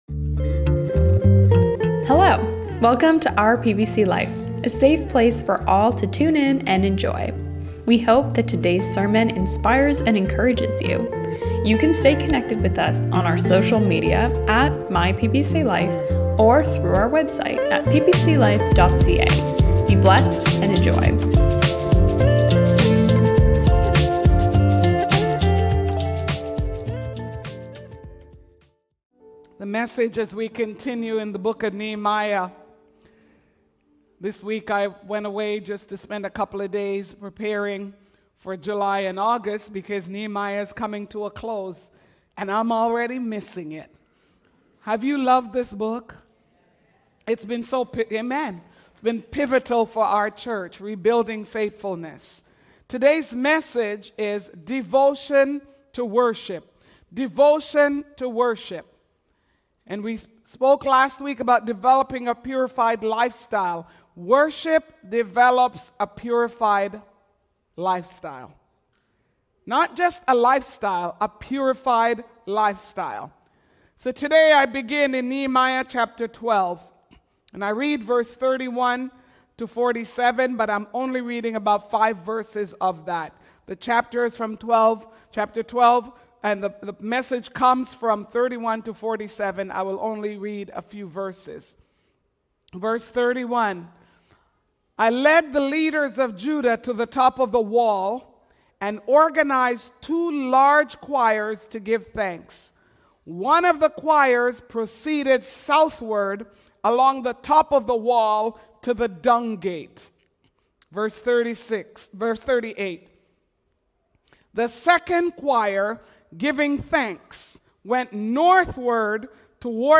we trust that this message encourages you and inspires you to worship!!